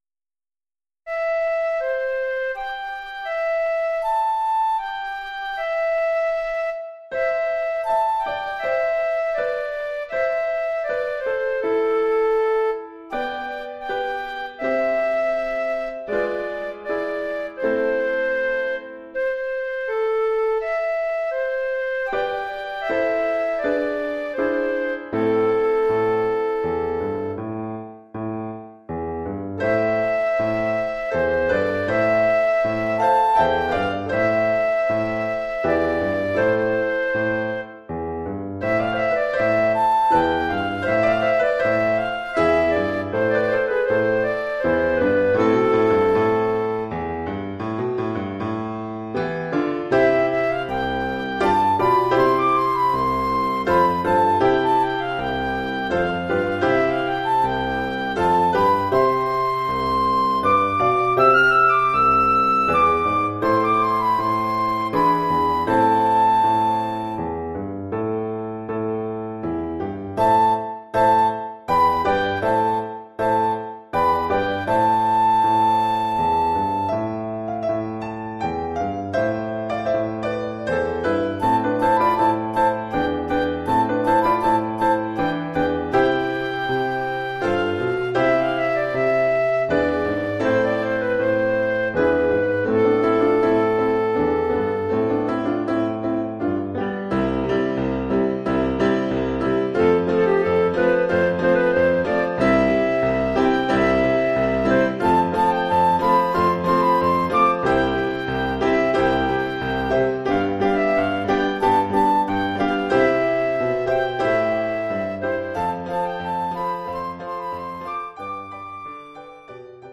Formule instrumentale : Flûte et piano
Oeuvre pour flûte et piano.